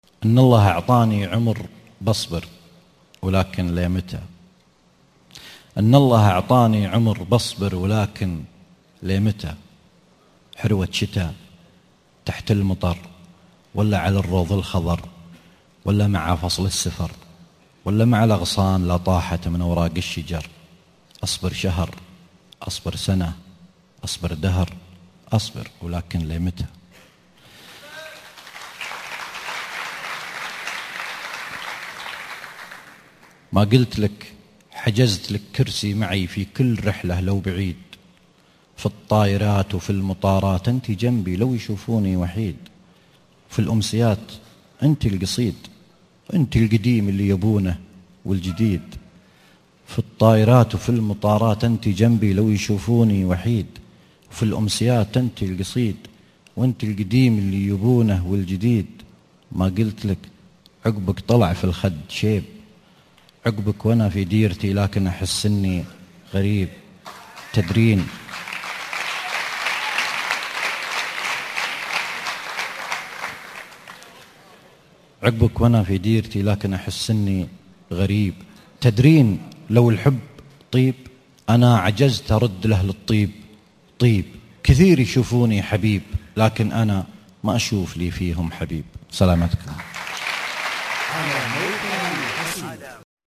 لي متى ( امسية سان دييغو 2013 )